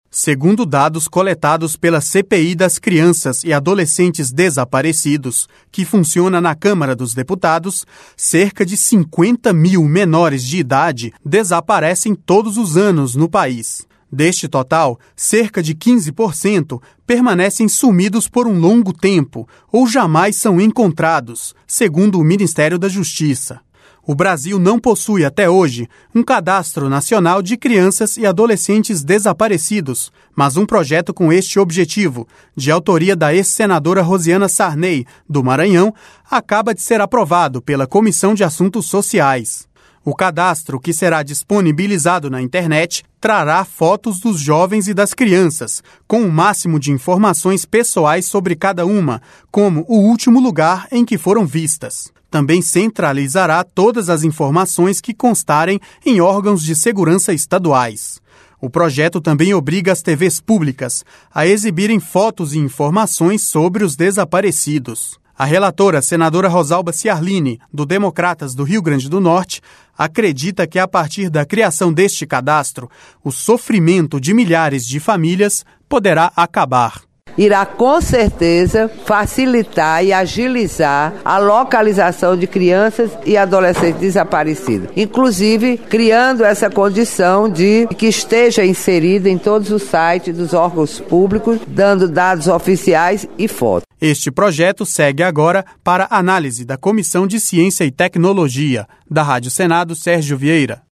A relatora, senadora Rosalba Ciarlini do Democratas do Rio Grande do Norte, acredita que a partir da criação deste cadastro o sofrimento de milhares de famílias poderá acabar.